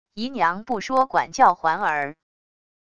姨娘不说管教环儿wav音频生成系统WAV Audio Player